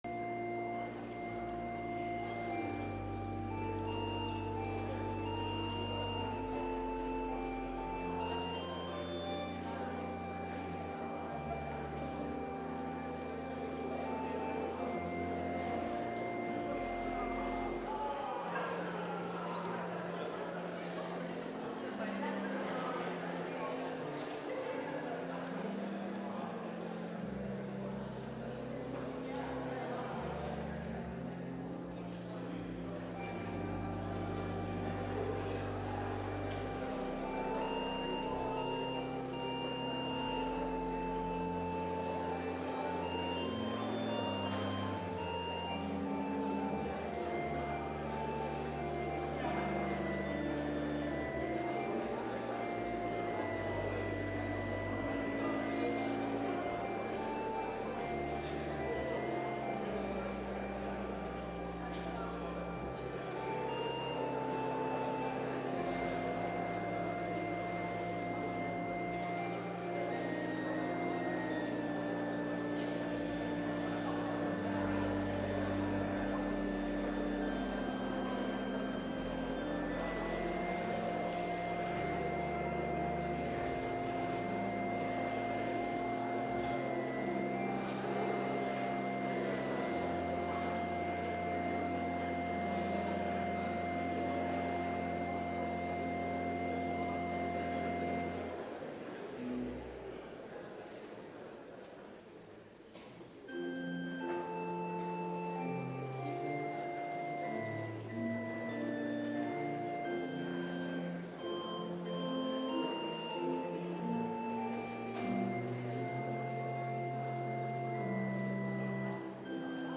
Complete service audio for Chapel - Monday, April 15, 2024